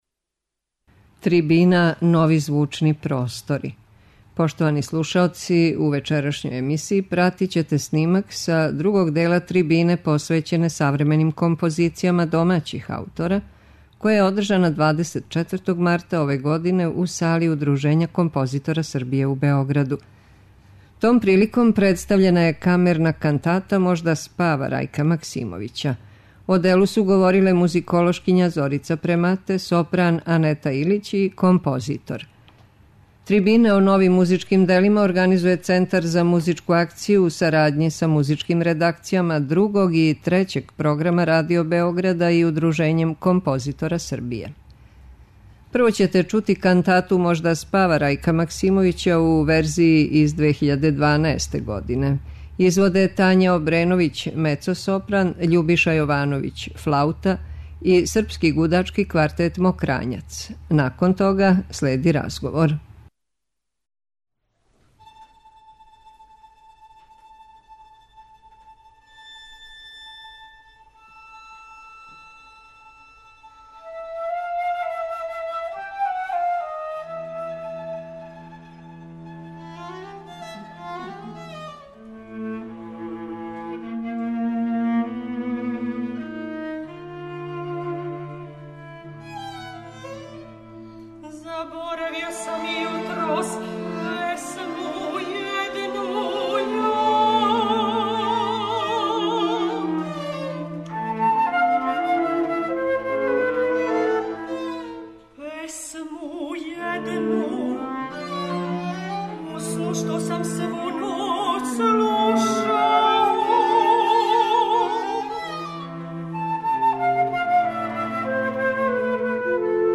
Слушаћете снимак са другог дела трибине посвећене савременим композицијама домаћих аутора, која је одржана 24. марта ове године у Сали Удружења композитора Србије у Београду.